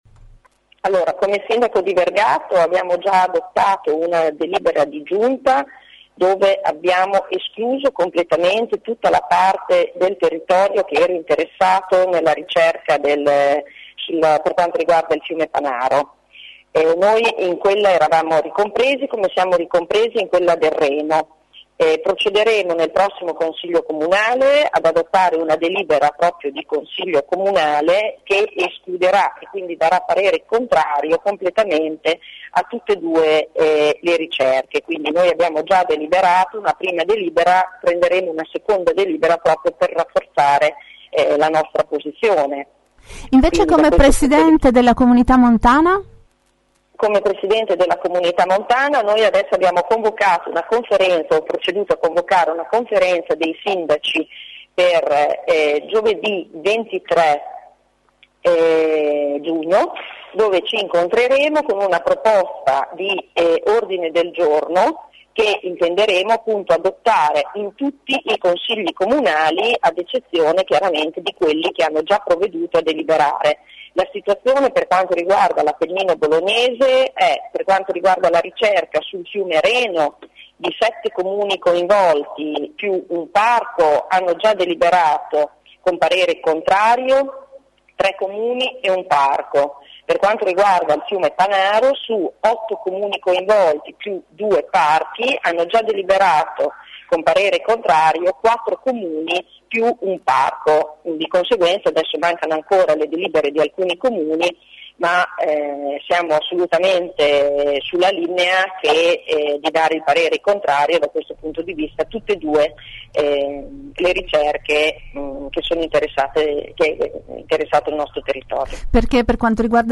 Ma gli enti locali si stanno muovendo in direzione opposta, il sindaco di Vergato Sandra Focci è anche presidente della Comunità Montana dell’area. Nell’intervista ci spiega che il consiglio comunale di Vergato delibererà contro le ricerche di idrocarburi su Panaro e Reno mentre giovedì 23 giugno la Comunità montana riunirà tutti i sindaci per organizzare il fronte del no.
sindaco-vergato-per-post.mp3